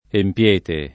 empL%te]), il pres. cong. (empia [%mpLa], ecc.), l’imper. sing. empi [